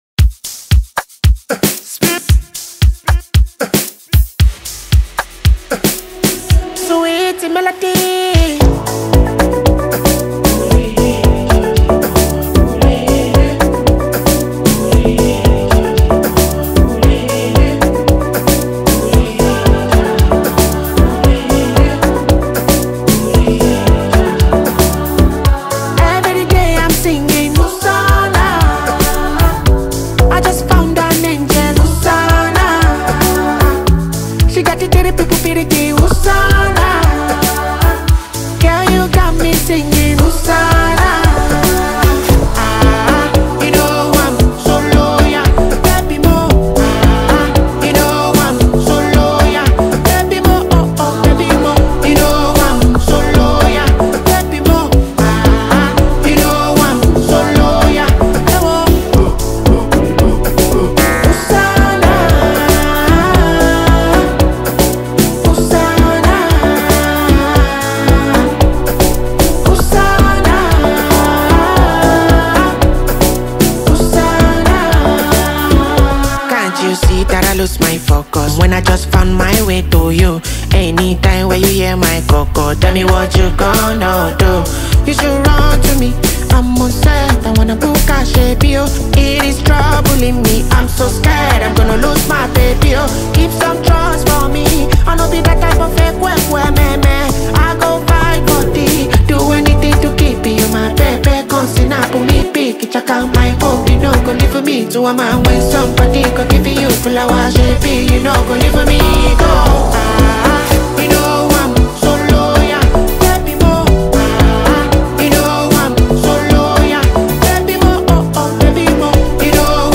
A Powerful Afrobeat Anthem of Devotion
soulful delivery